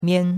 mian1.mp3